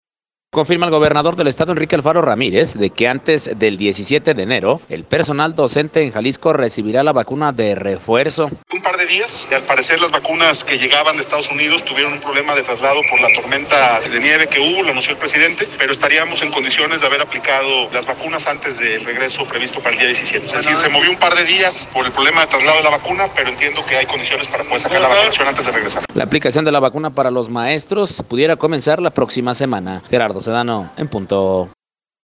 Confirma el gobernador del estado, Enrique Alfaro Ramírez, que antes del 17 de enero, personal docente en Jalisco, recibirá la vacuna de refuerzo: